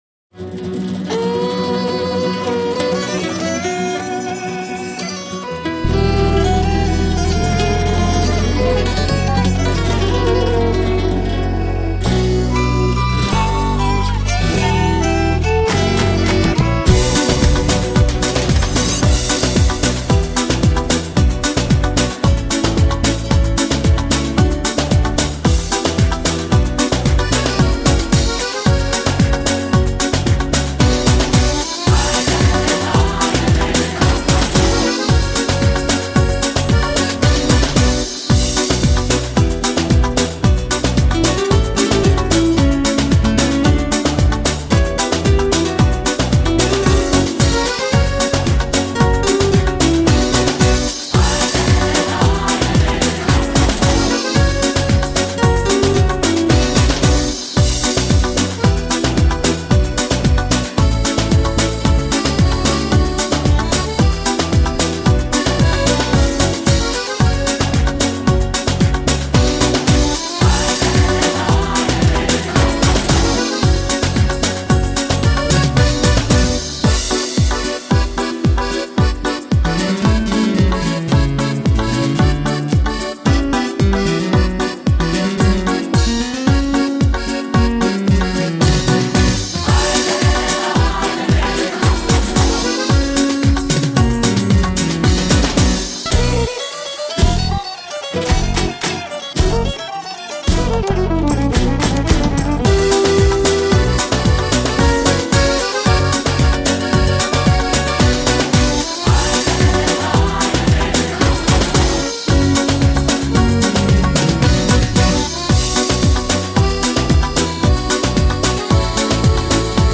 Зажигательные Цыганские Мотивы